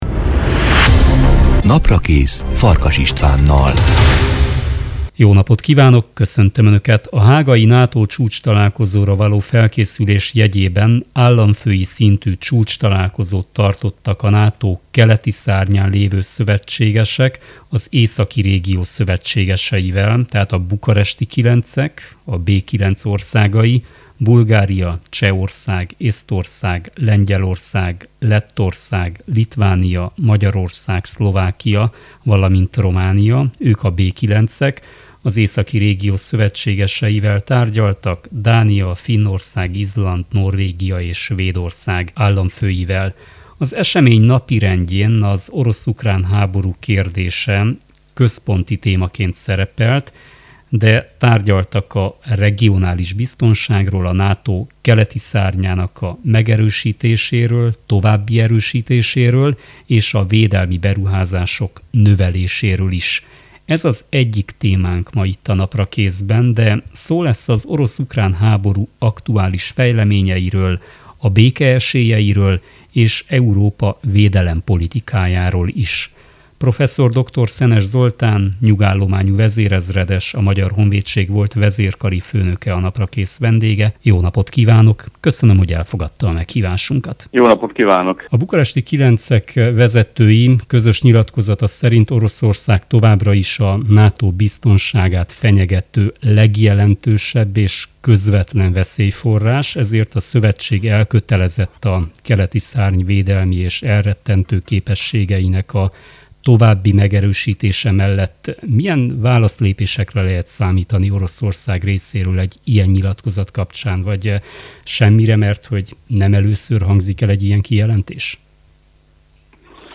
Prof. Dr. Szenes Zoltán nyugállományú vezérezredes, a Magyar Honvédség volt vezérkari főnöke, egyetemi tanár a vendégem.